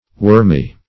wormy - definition of wormy - synonyms, pronunciation, spelling from Free Dictionary
Wormy \Worm"y\, a. [Compar. Wormier; superl. Wormiest.]